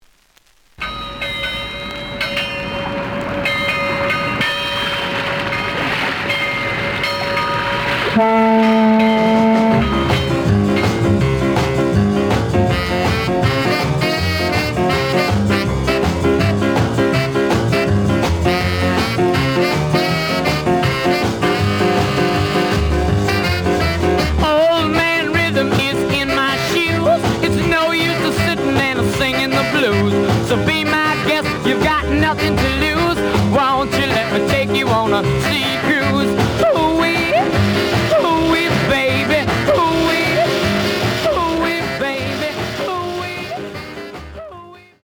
The audio sample is recorded from the actual item.
●Genre: Rhythm And Blues / Rock 'n' Roll
B side plays good.)